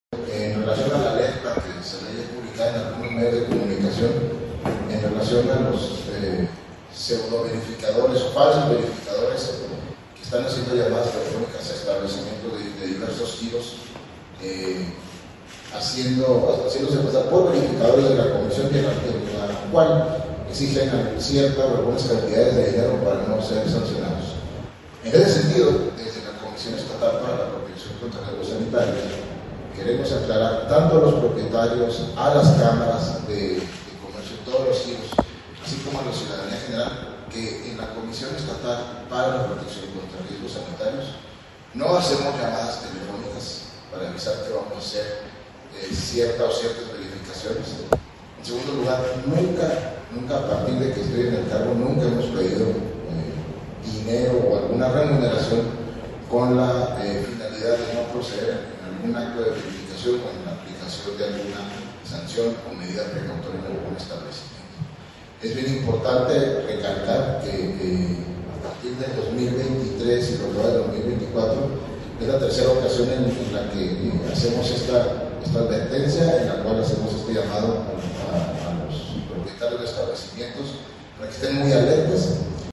AUDIO: LUIS CARLOS TARÍN, TITULAR DE LA COMISIÓN ESTATAL PARA LA PROTECCIÓN CONTRA RIESGOS SANITARIOS ( COESPRIS )